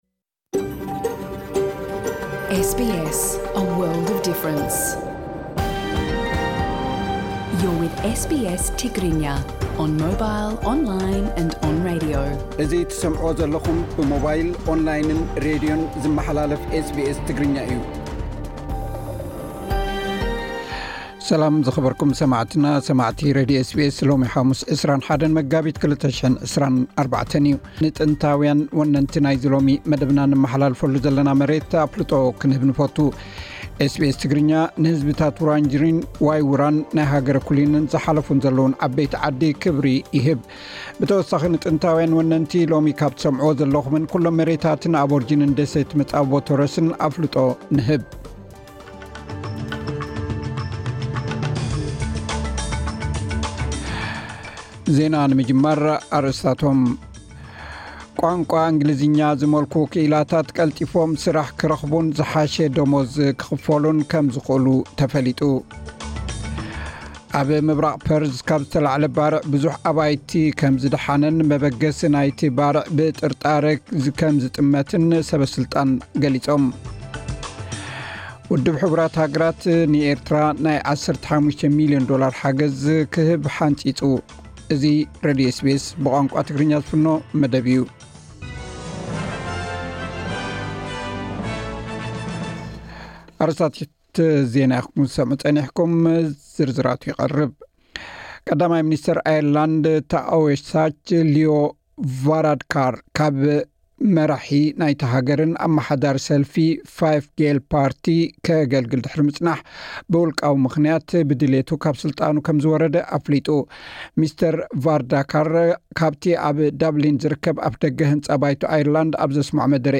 ዕለታዊ ዜና ኤስ ቢ ኤስ ትግርኛ (21 መጋቢት 2024)